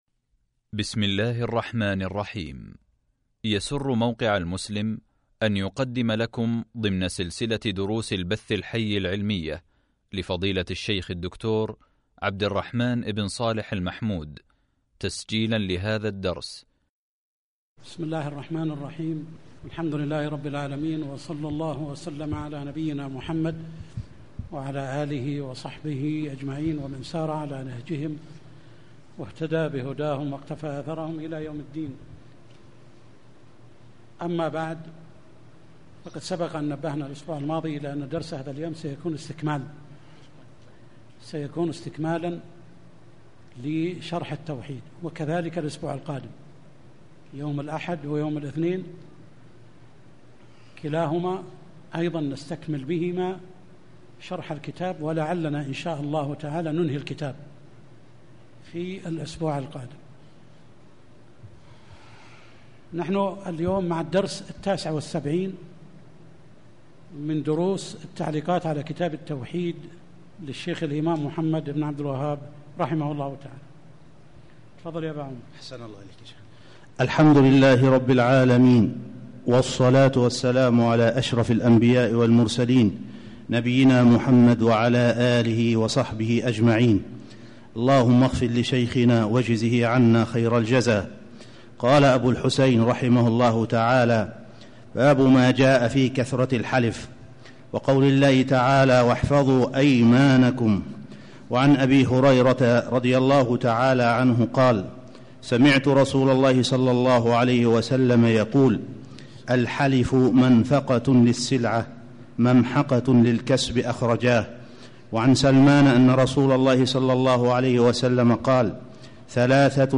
الدرس 79 كتاب التوحيد | موقع المسلم